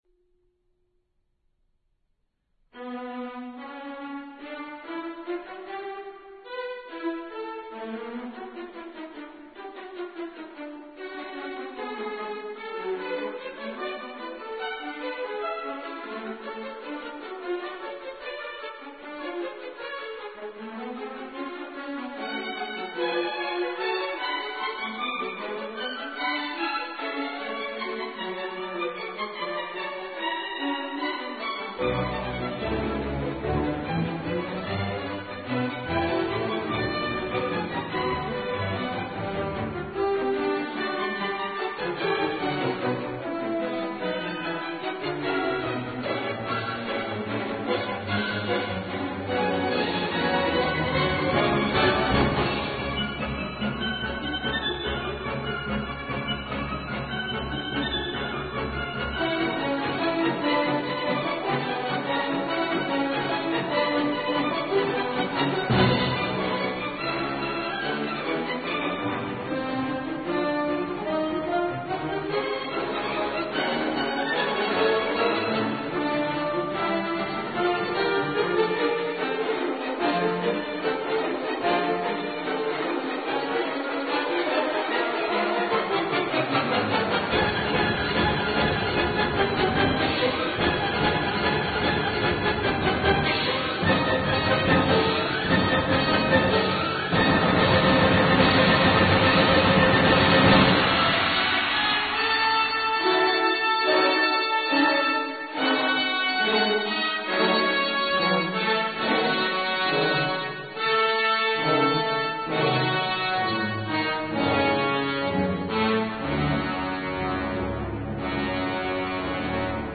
بخش بسط و گسترش این موومان با فوگی چهار صدایی و پر قدرت برای سازهای زهی که سوژه اش از همان تم اصلی سمفونی مشتق شده، آغاز می گردد، هرچه پیشتر می رود، حجم صوتی نیز گسترش یافته، سازها یکی پس از دیگری پا به عرصه گذاشته و کرشندویی عظیم ایجاد گردیده و سپس فرو می نشیند و آرامشی ترکیب شده با استرس، ناامیدی، اندوه و اضطراب سراسر موومان را فرا می گیرد.